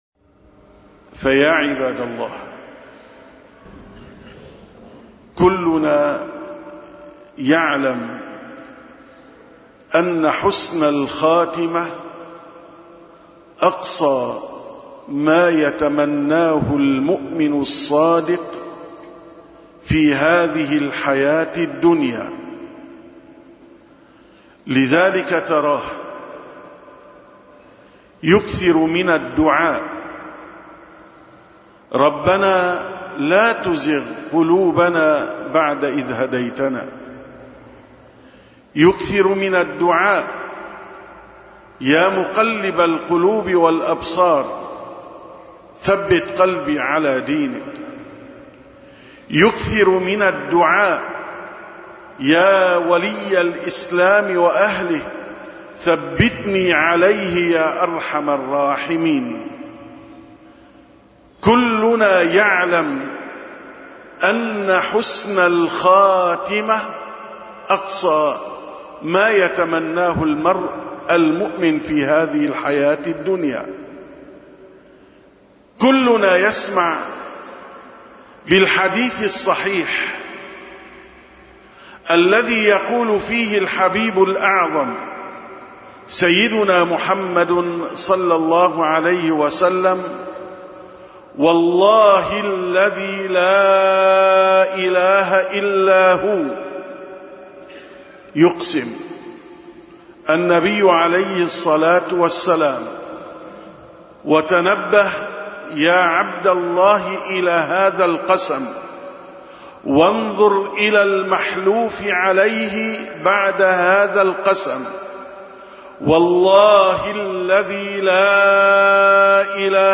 870ـ خطبة الجمعة: حسن السيرة سبب لحسن الخاتمة